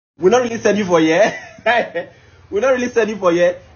we-go-really-send-you-for-here-comedy-meme.mp3